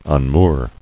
[un·moor]